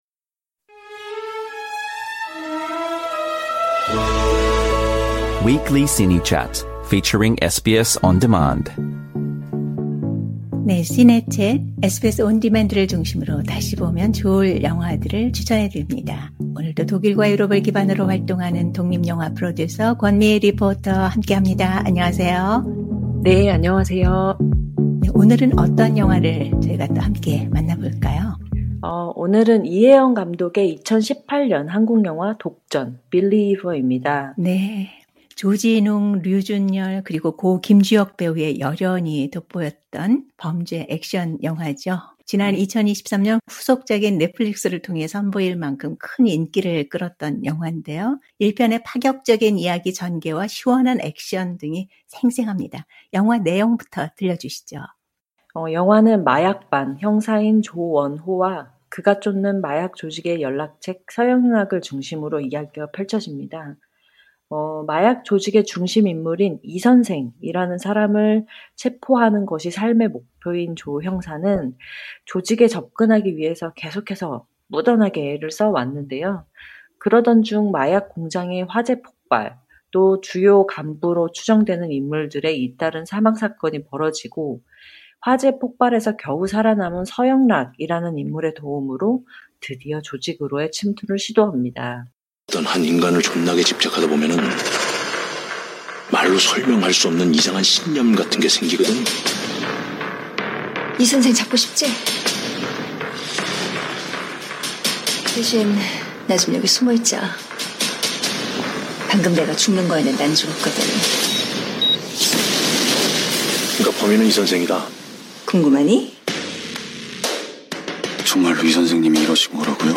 Trailer Audio Clip